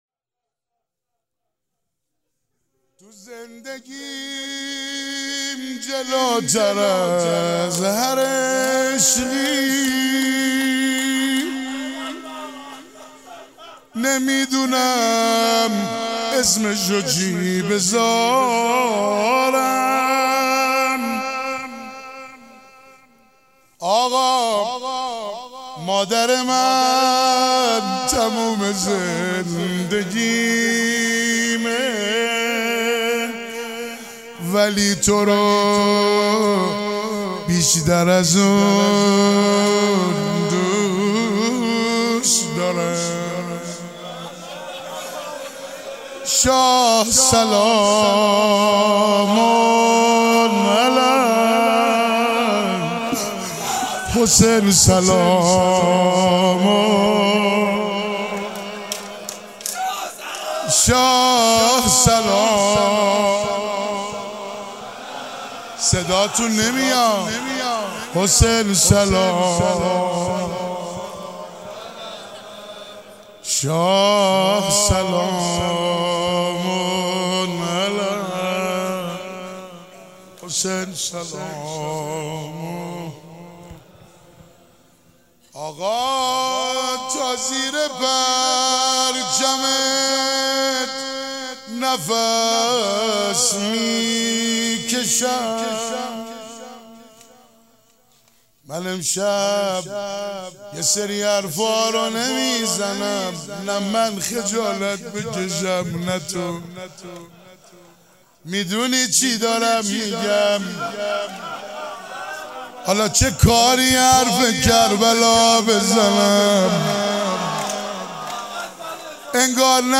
روضه.mp3